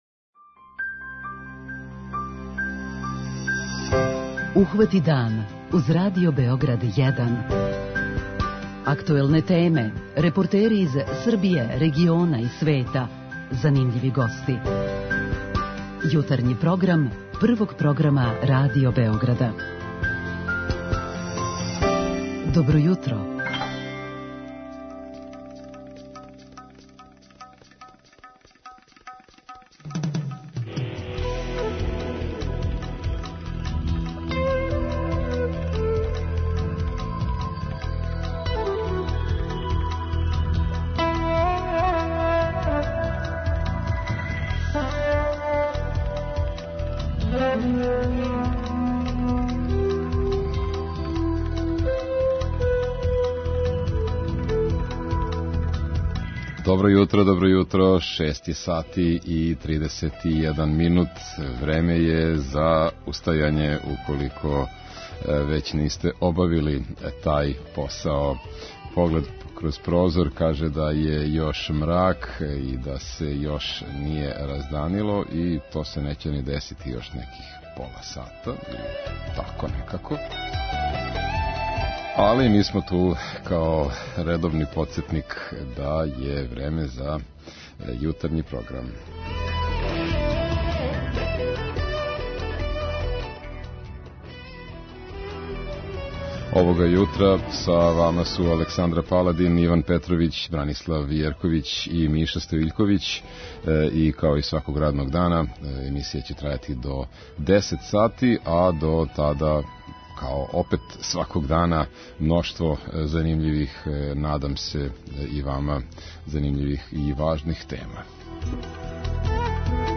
Пробудите се уз Радио Београд 1!